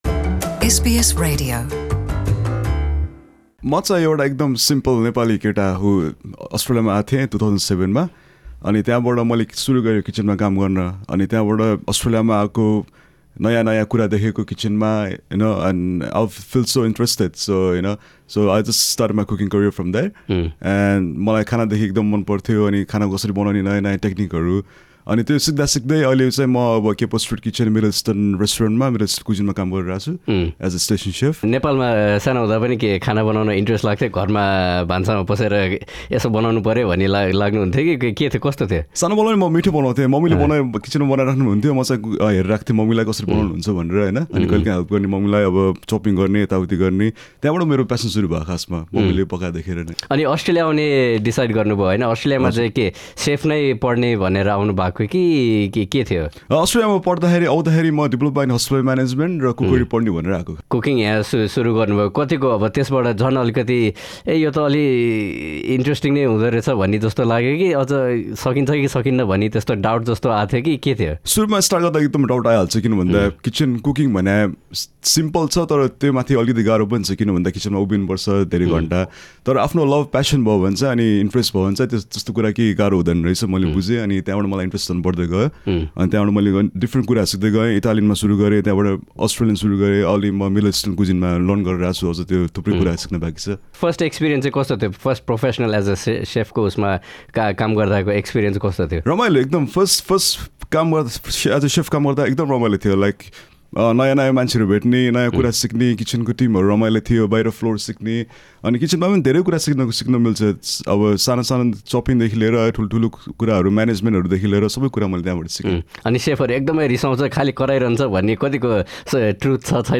हाम्रो कुराकानी